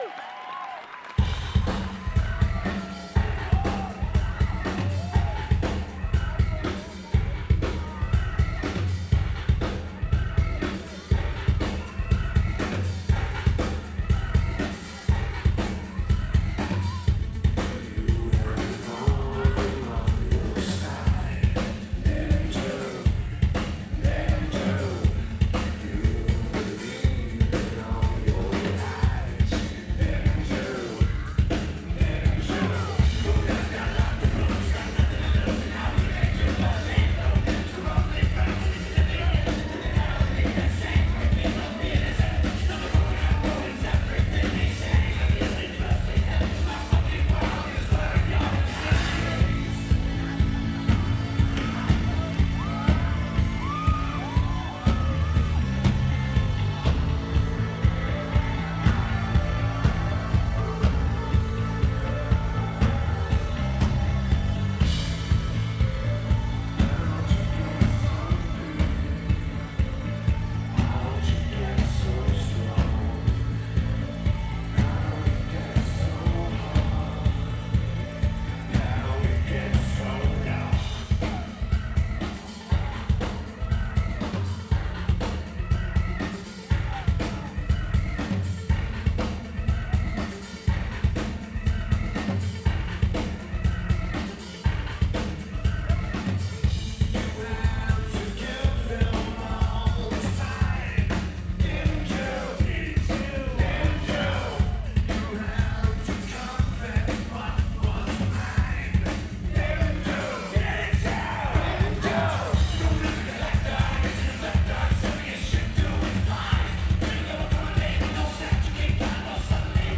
Copenhagen Denmark
Lineage: Audio - AUD (?? + Voice Attenuator + Sony MZ-R55)